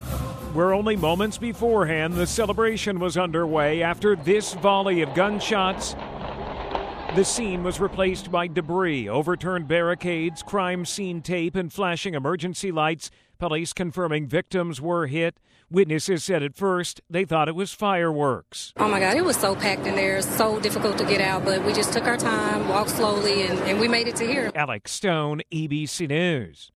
Audio courtesy of ABC News.